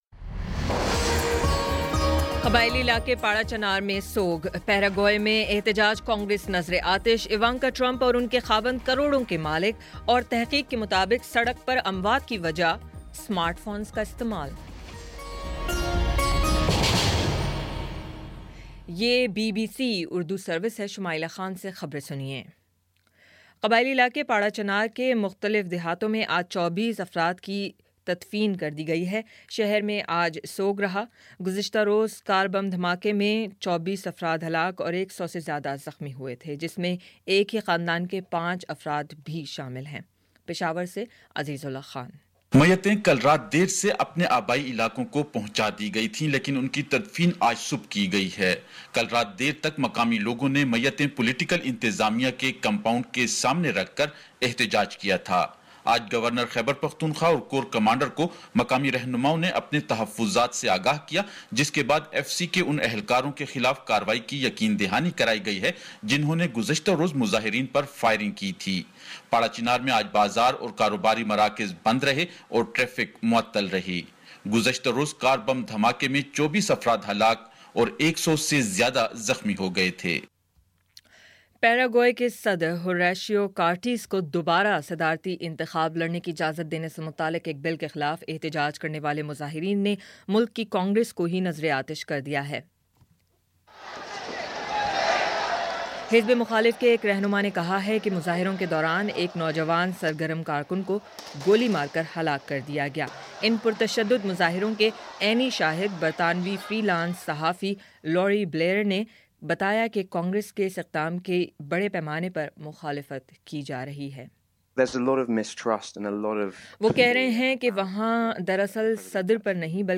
اپریل 01 : شام پانچ بجے کا نیوز بُلیٹن